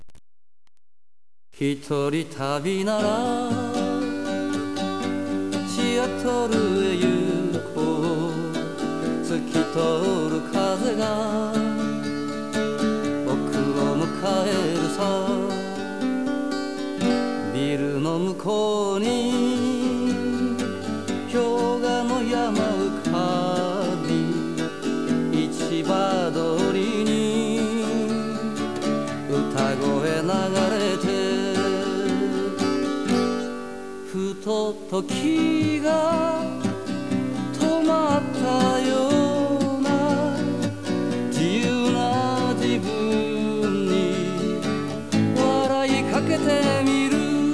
今年の４月、シアトルで録音しました。